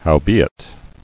[how·be·it]